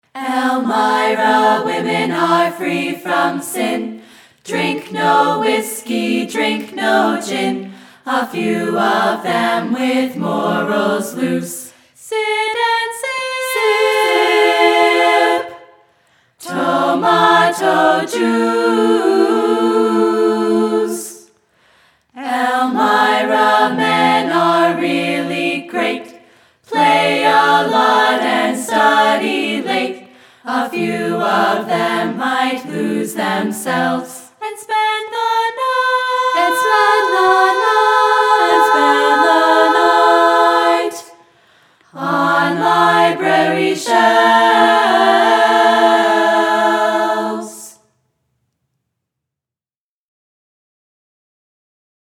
Click on the "Listen (MP3)" button to hear the song performed by the EC Chiclettes where available.